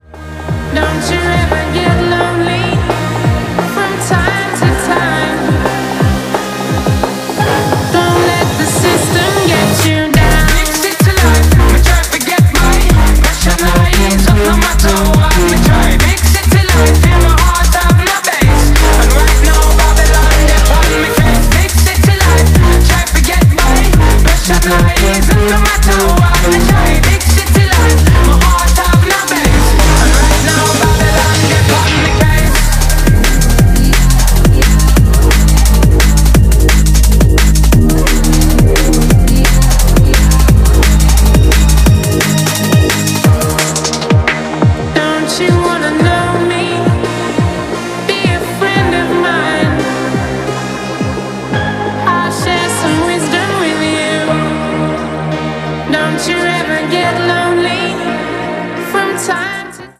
Yoooo it's OK to leave a bit of a gap between voice overs sis otherwise it will sound like multiple people taking over each other.